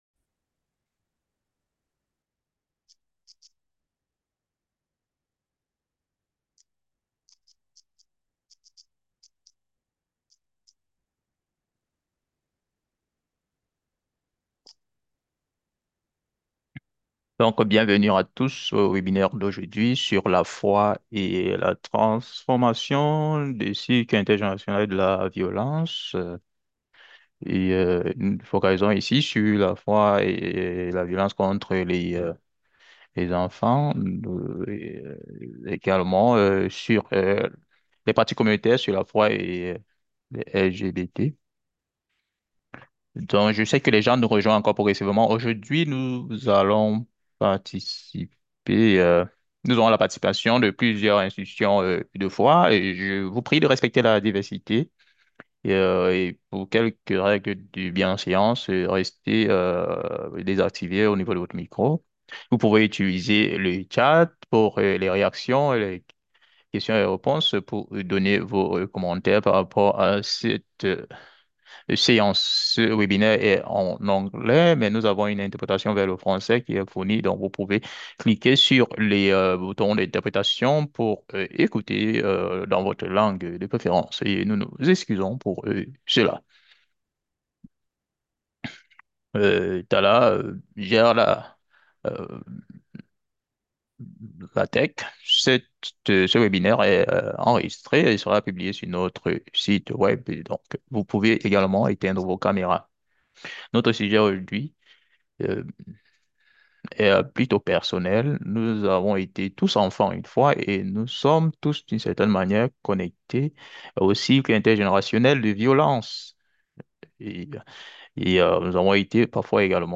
Panellists from diverse religious traditions, professional backgrounds, and personal experiences engaged in a multi-way dialogue on the beliefs, values, and trauma patterns that shape childhoods and perpetuate these cycles.
French-interpretation_CoP-Webinar-Nov-18.m4a